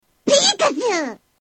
Peeekachu Sound Effect Free Download